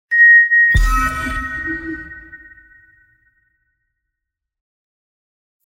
シンプルな通知音。